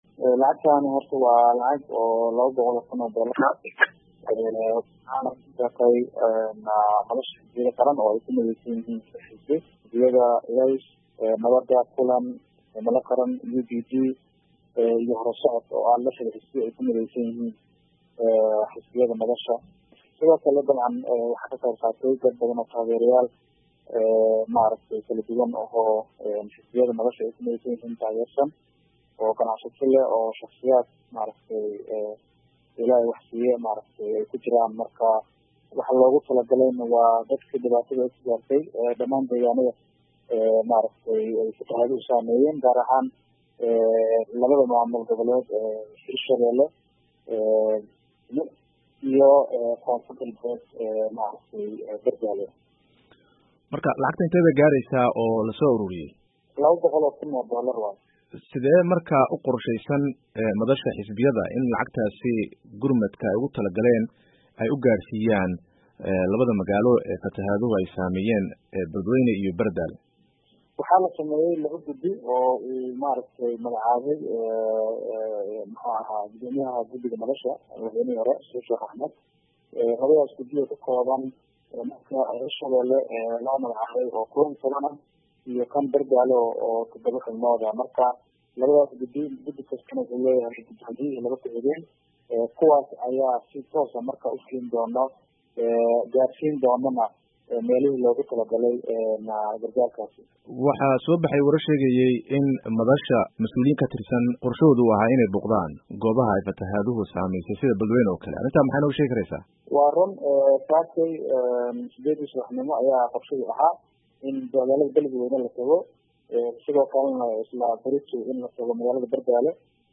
Wareysiga senator Ilyaas